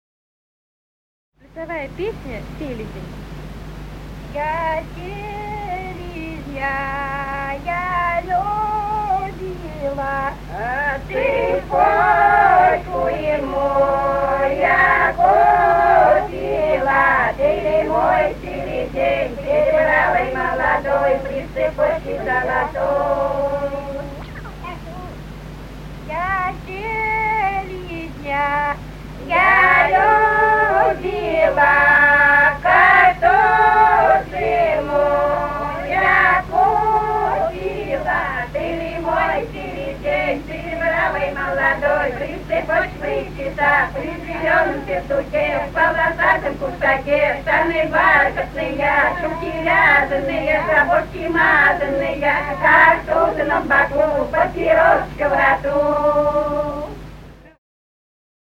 Русские народные песни Владимирской области [[Описание файла::11. Я селезня, я любила (хороводная) стеклозавод «Красный химик» Судогодского района Владимирской области.